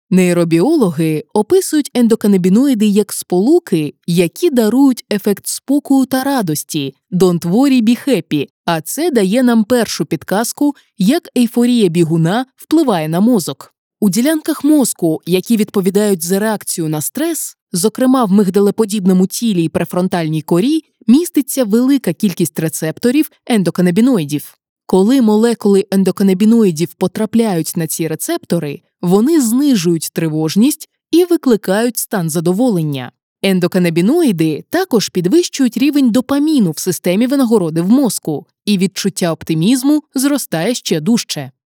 Popular Science Audiobook (Ukrainian)
Ukrainian Audiobook VoiceOver: "The Real Matrix”
My delivery ranges from calm, sophisticated narration and warm commercial tones to expressive, high-energy storytelling.
Ukrainian Popular Science Audiobook Excerpt_0.wav